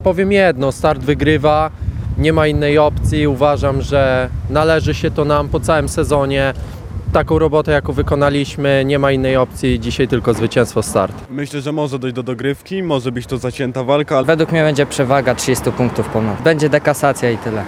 Tłumy kibiców czekają na wejście do Hali Globus, gdzie zostanie rozegrane to spotkanie.